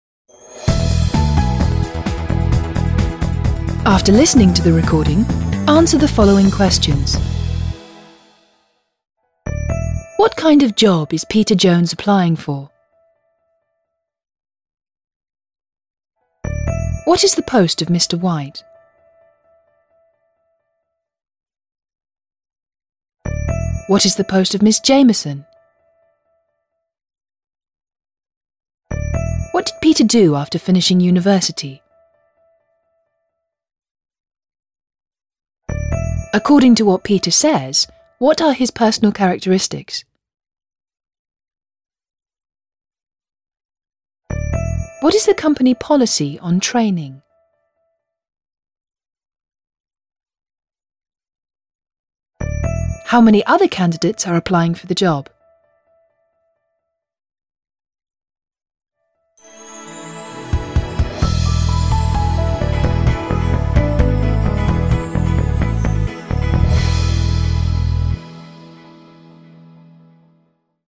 Job interview - Sales Manager, Dialog  602KB